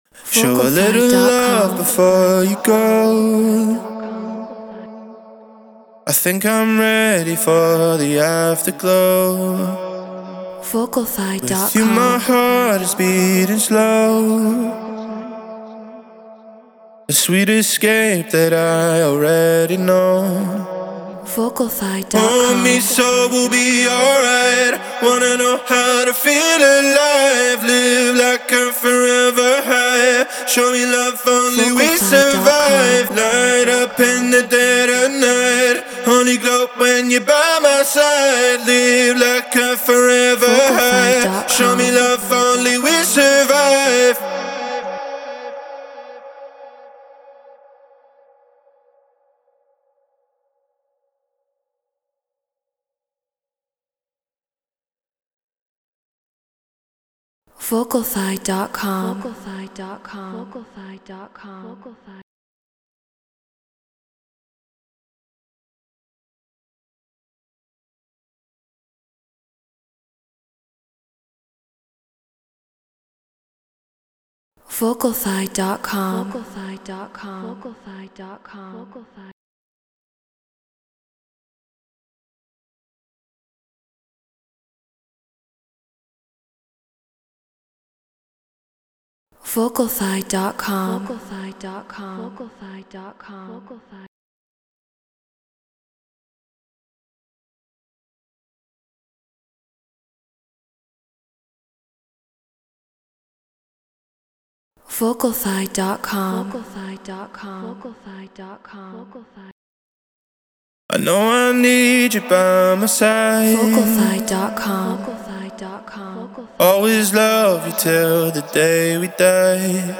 Drum & Bass 174 BPM Bmin
Shure SM7B Apollo Twin X Logic Pro Treated Room